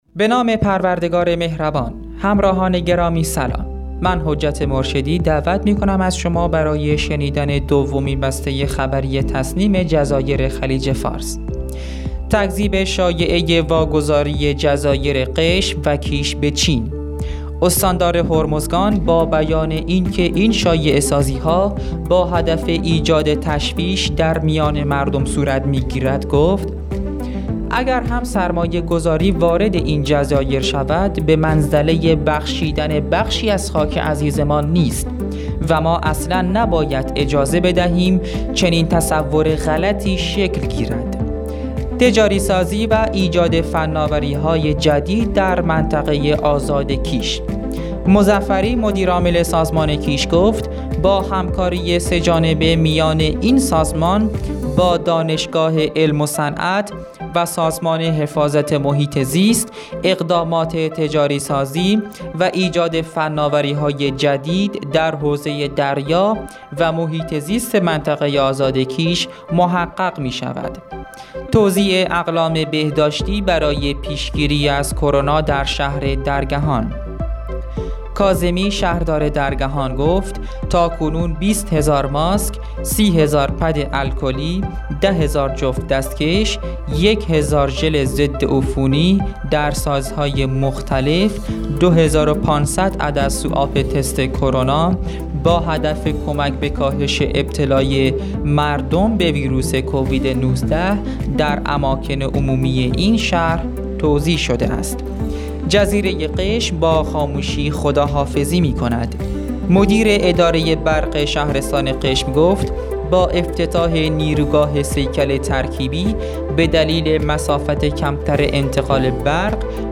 به گزارش خبرگزاری تسنیم از قشم, دومین بسته خبری رادیو تسنیم از جزایر خلیج فارس را با خبرهایی چون تکذیب شایعه واگذاری جزایر قشم و کیش به چین، برنامه‌ریزی افزایش کیفیت زندگی ساکنان قشم با اجرای طرح جامع، تجاری سازی و ایجاد فناوری های جدید در منطقه آزاد کیش، توزیع اقلام بهداشتی برای پیشگیری از کرونا در شهر درگهان، جزیره قشم با خاموشی خداحافظی می‌کند، 7.5 تُن ماهی قاچاق در آب‌های کیش کشف شد منتشر یافت.